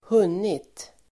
hunnit.mp3